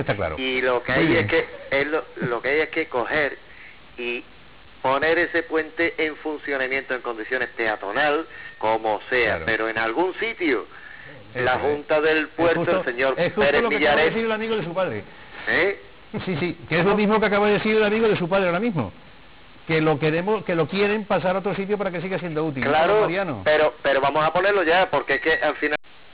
A finales de 1997 se organizó un debate televisivo en Onda Giralda Televisión, en el cual se mostraron las diferentes posturas existentes sobre el Puente de Alfonso XIII.
También se escuchó la opinión de los oyentesque llamaron al programa:
Primer oyente